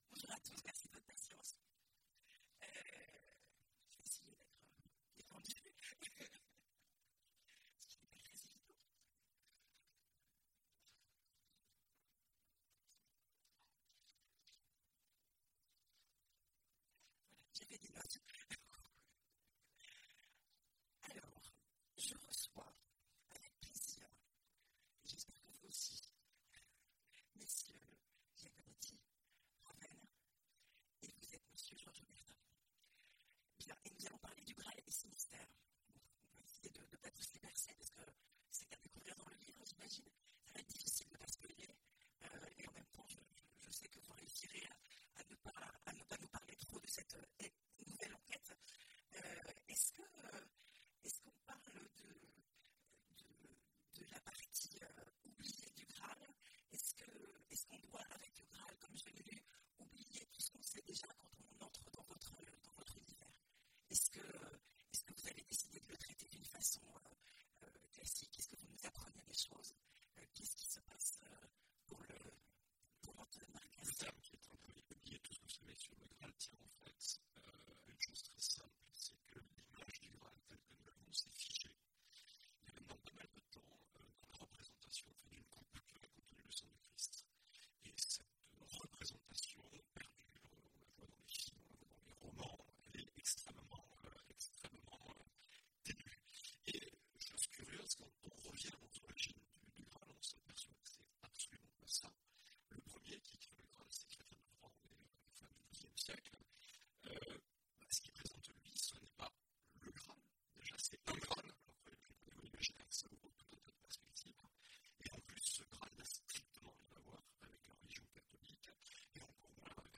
Imaginales 2016 : Conférence Le Graal…